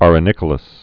(ărə-nĭkə-ləs)